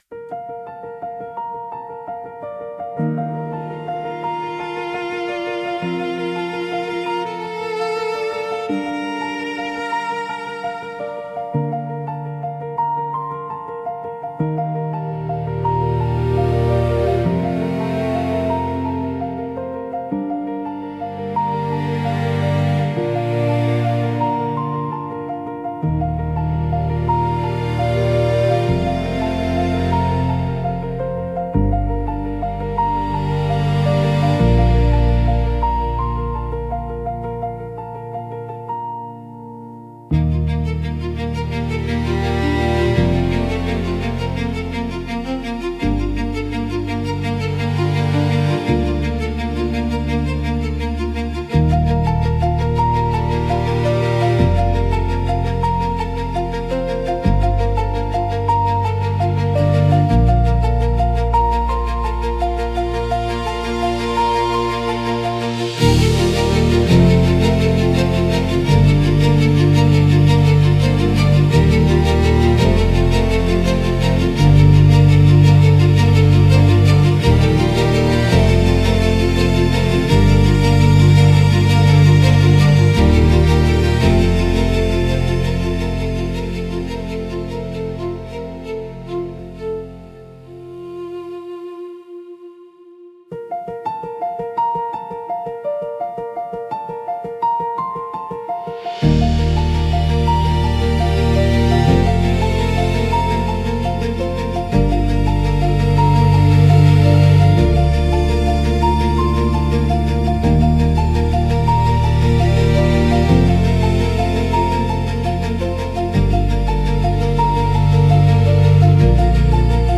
Grateful Praise Instrumental